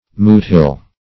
Search Result for " moot-hill" : The Collaborative International Dictionary of English v.0.48: Moot-hill \Moot"-hill`\, n. (O. Eng.